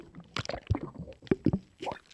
喝水2zth070518.wav
通用动作/01人物/02普通动作类/喝水2zth070518.wav